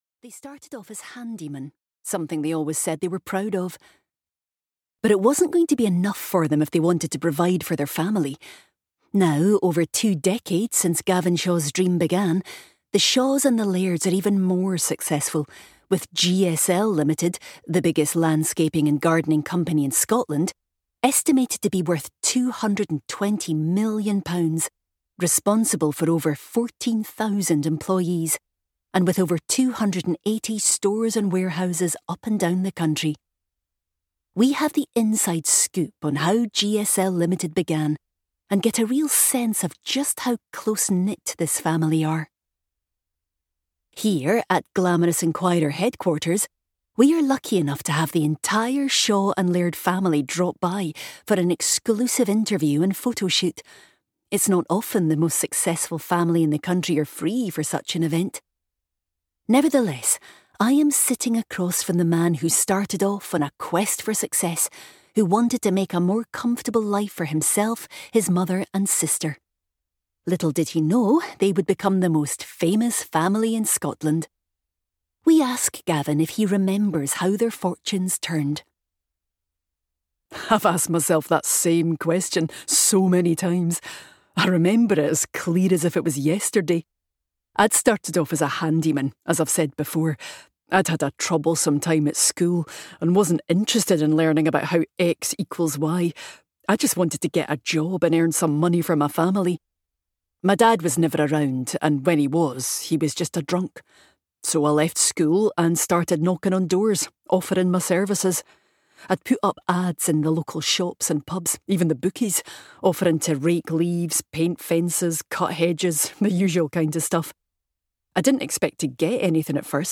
She Who Lies (EN) audiokniha
Ukázka z knihy